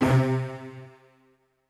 STR HIT C2.wav